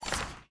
ui_back_click.wav